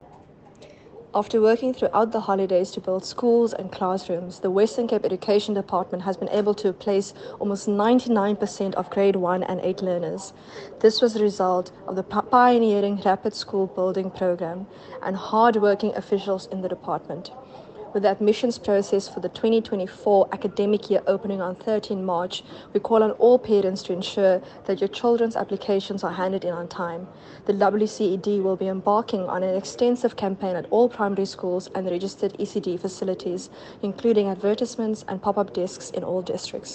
English soundbite.